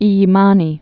(ēyē-mänē)